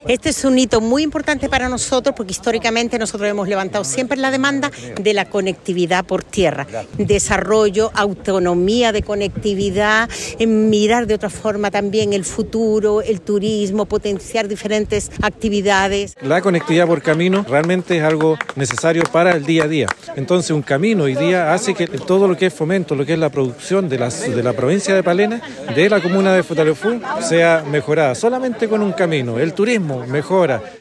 A los pies del Volcán Chaitén, en medio de la característica llovizna del sur, los gobernadores de la zona sur austral del país, firmaron su compromiso para trabajar en pos de la conectividad denominada “Chile por Chile”.
Esa confianza es compartida por los alcaldes de la provincia de Palena, tal como lo plantearon los alcaldes de Chaitén y Futaleufú, Clara Lazcano y Fernando Grandón, respectivamente.